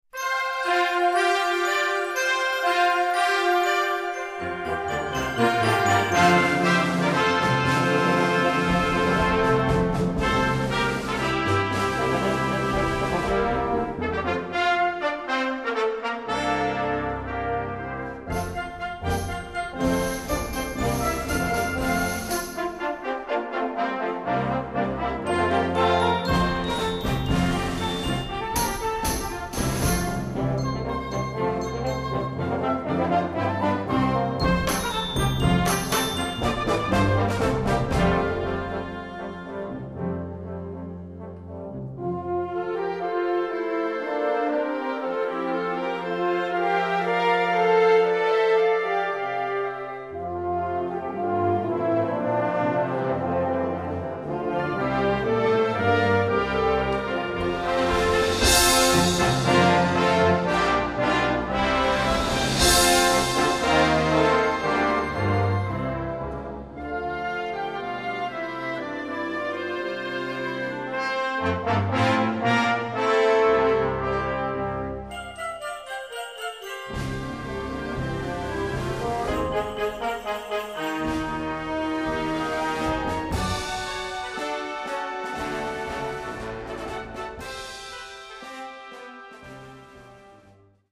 Répertoire pour Harmonie/fanfare - Concert Band ou Harmonie